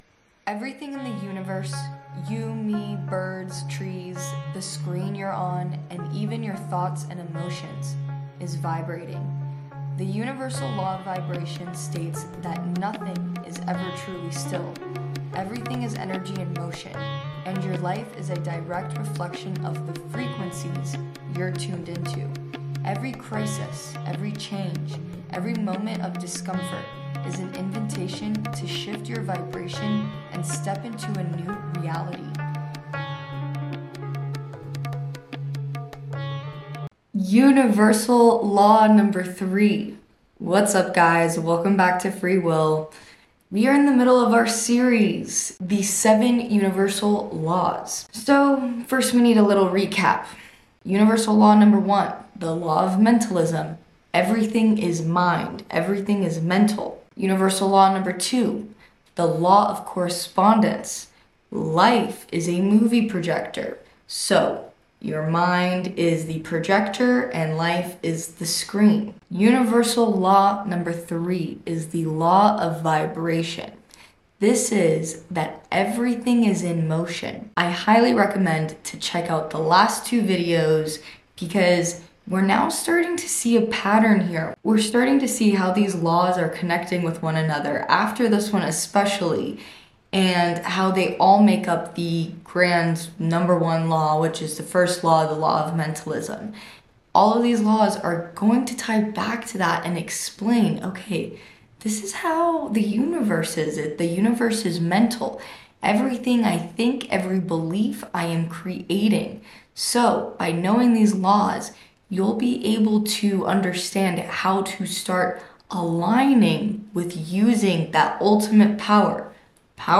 Dive into a transformative guided meditation that explores the power of surrender and the beauty of unpredictability. Just like a video game, life unfolds in unexpected ways, and true freedom comes when we release our grip on control.